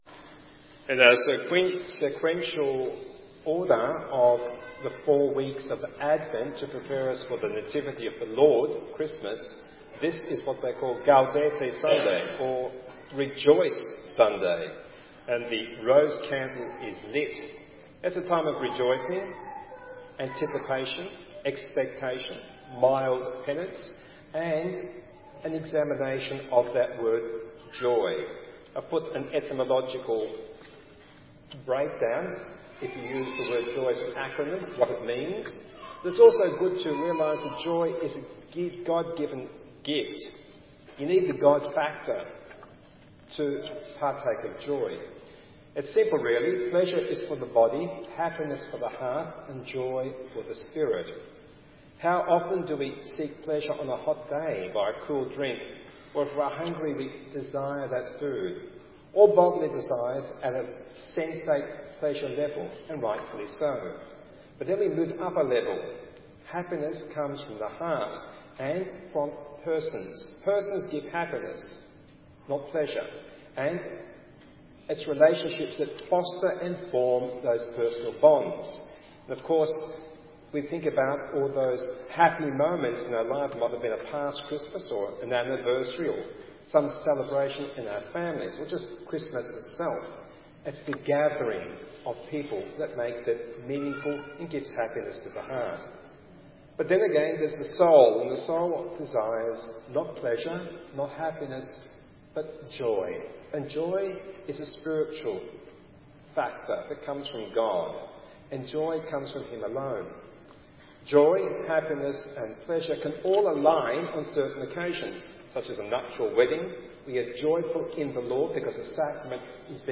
AUDIO GOSPEL REFLECTIONS ~ Third Sunday of Advent